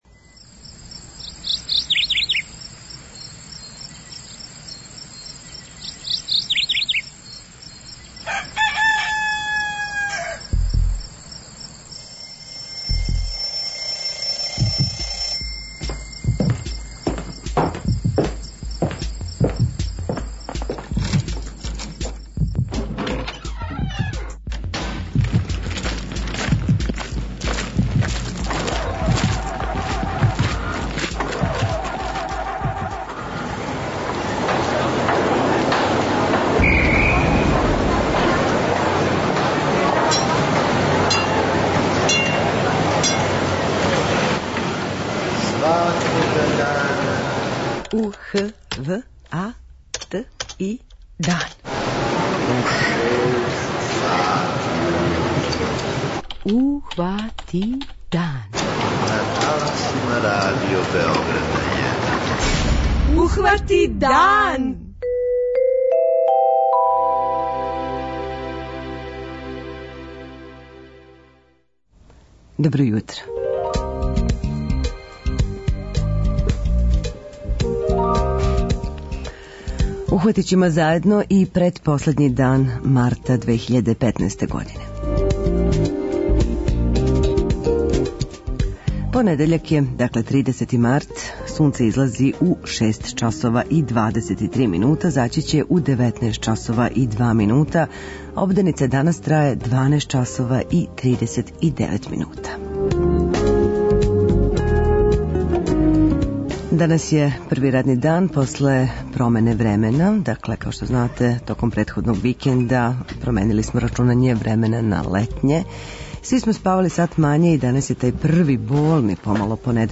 преузми : 43.16 MB Ухвати дан Autor: Група аутора Јутарњи програм Радио Београда 1!